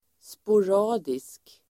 Uttal: [spor'a:disk]